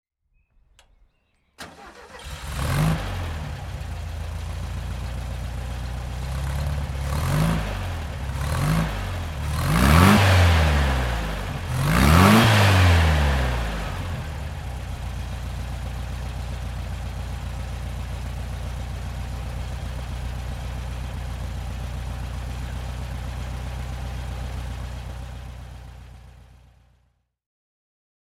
Apal Buggy C (1975) - Starten und Leerlauf